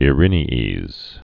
(ĭ-rĭnē-ēz)